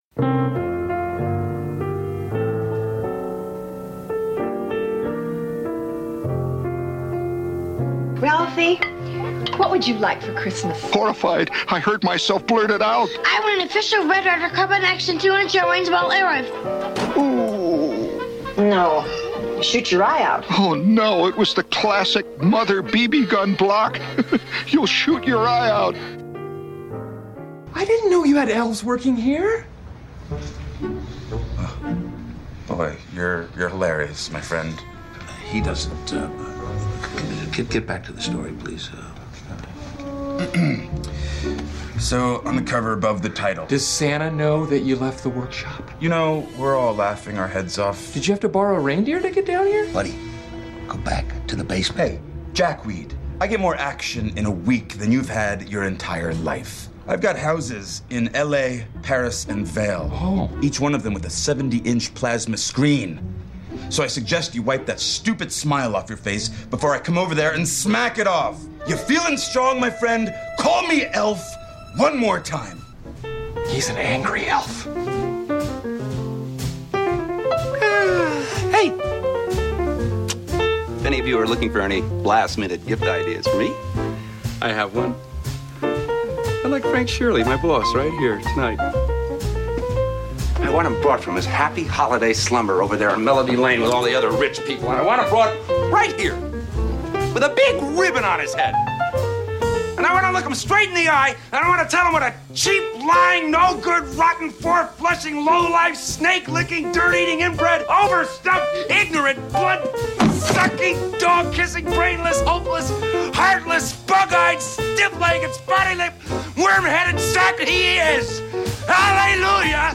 A clips show during the holidays…what kind of present is that?